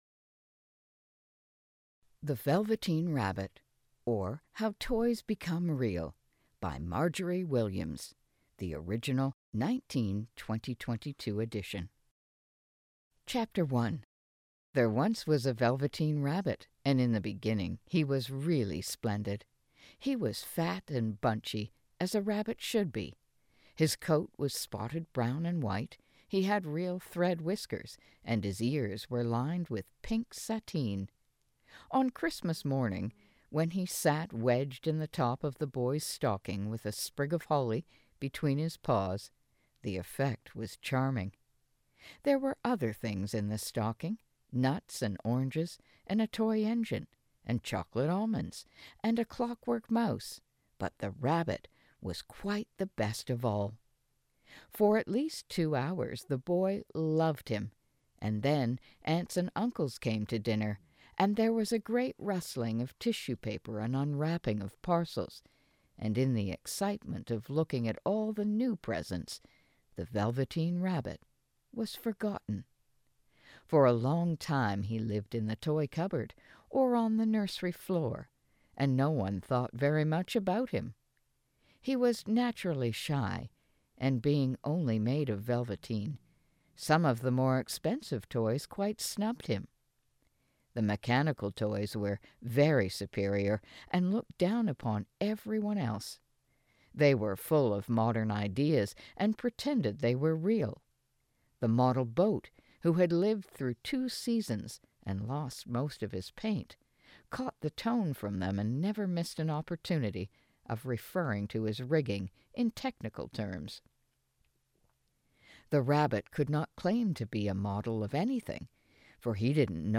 Narration.mp3